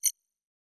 Holographic UI Sounds 105.wav